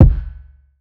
Wrong Kick.wav